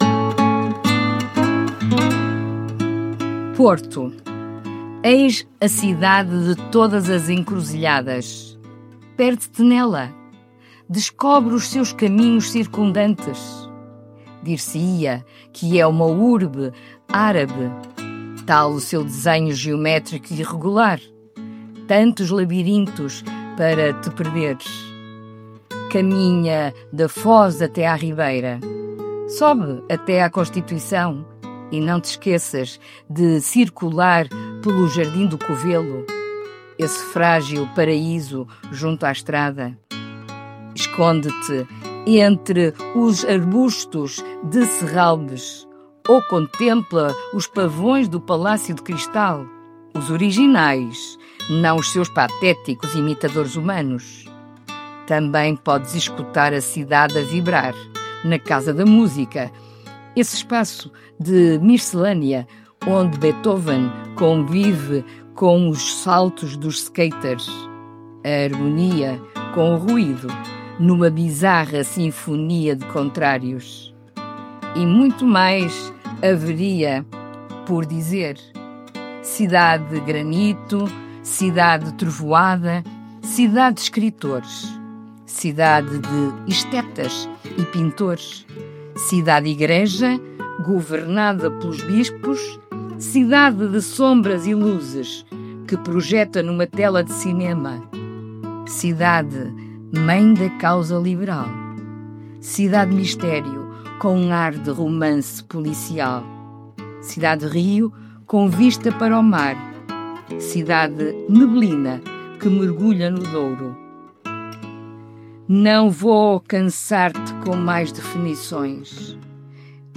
Música: Tender Classical Guitar, por LindsjoMusic, Licença Envato – Free Files Single Use Policy.